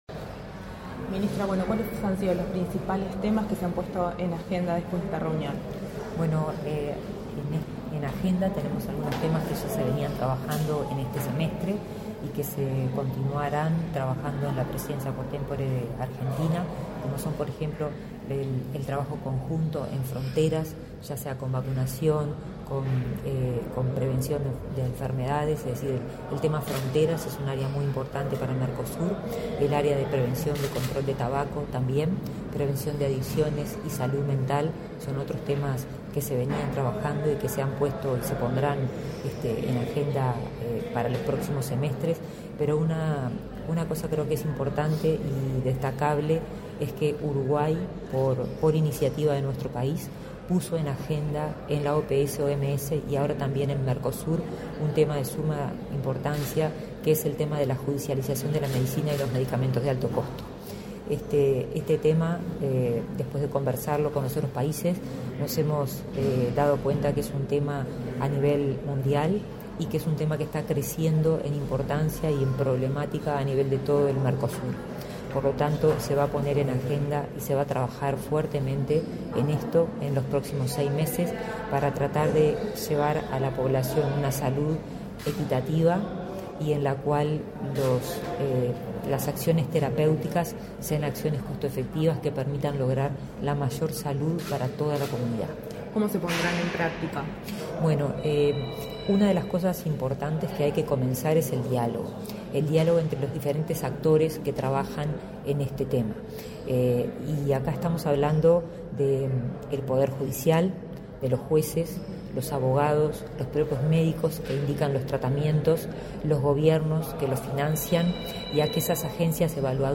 Entrevista a la ministra de Salud Pública, Karina Rando
Este 15 de noviembre se realizó la LV Reunión de Ministros de Salud del Mercosur en Montevideo.
En la oportunidad, la ministra de Salud Pública, Karina Rando, realizó declaraciones a Comunicación Presidencial.